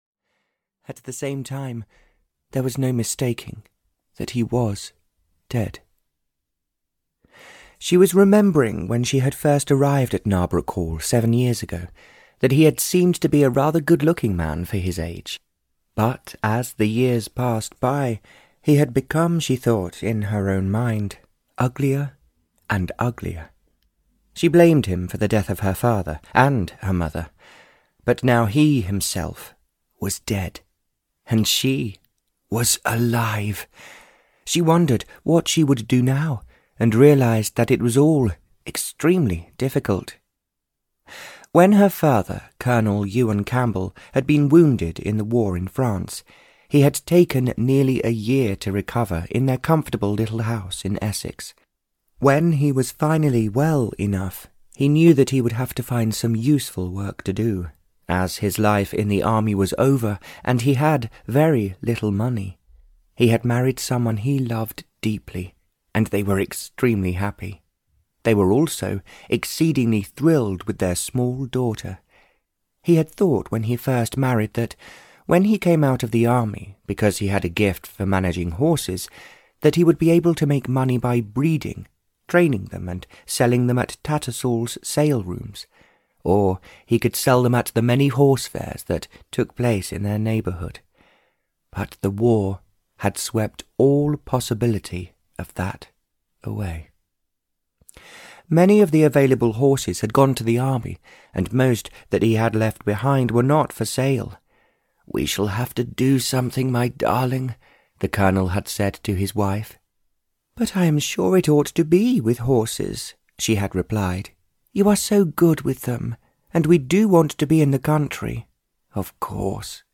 A Golden Lie (Barbara Cartland’s Pink Collection 113) (EN) audiokniha
Ukázka z knihy